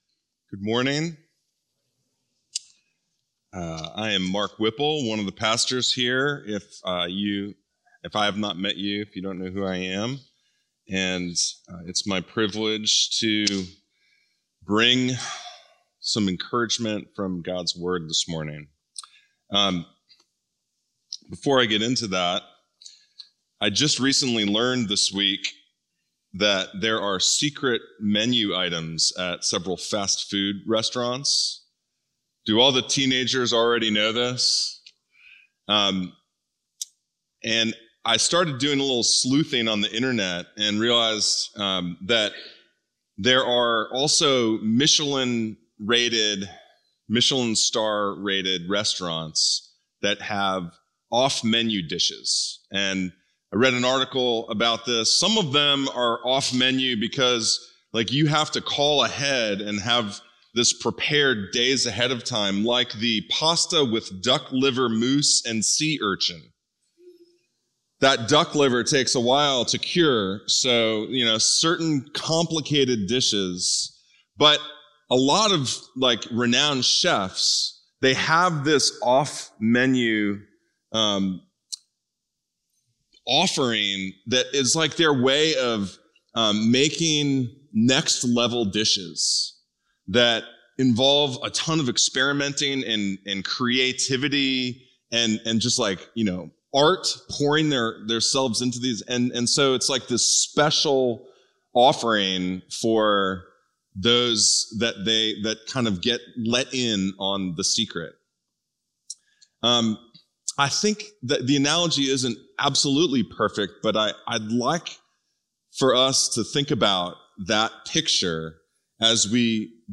View this week’s sermon discussion questions .